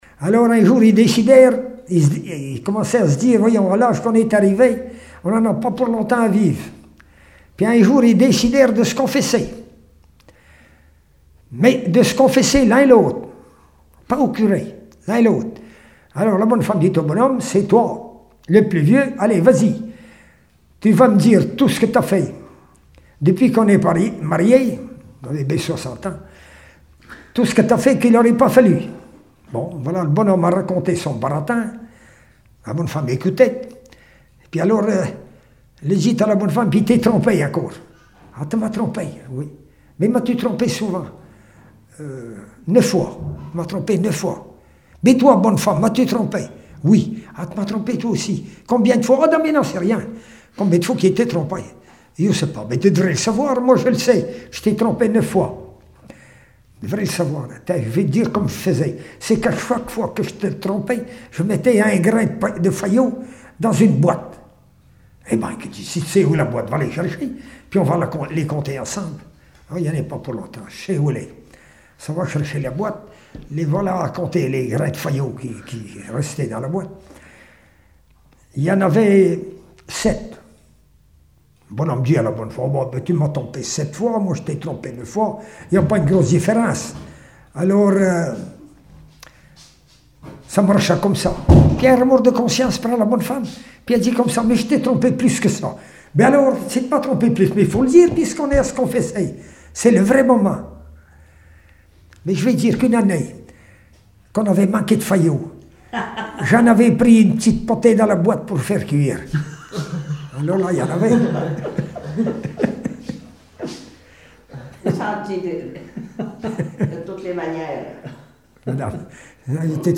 Genre sketch
Témoignages et chansons
Catégorie Récit